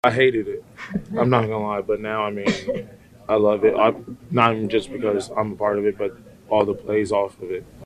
Connor Heyward wasn’t a fan of the play when they first started practicing it, but he says offensive coordinator Arthur Smith has gotten very creative scheming it out turned it into a real weapon.